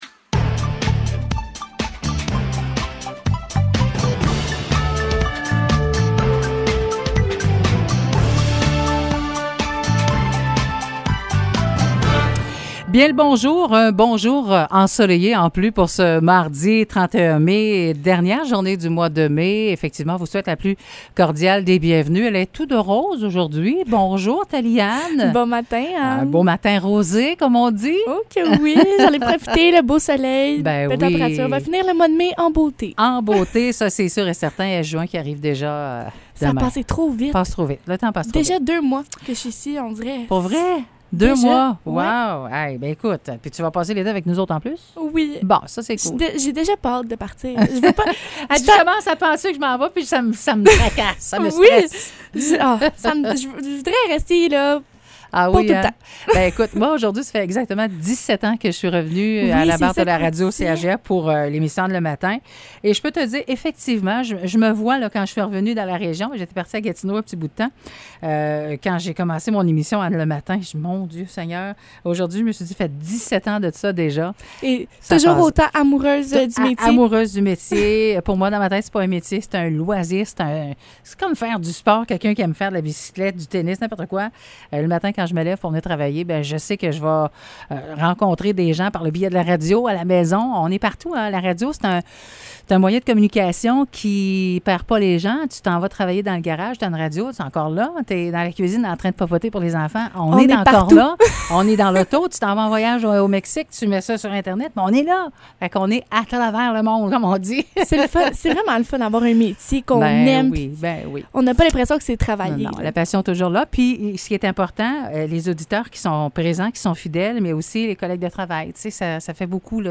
Nouvelles locales - 31 mai 2022 - 9 h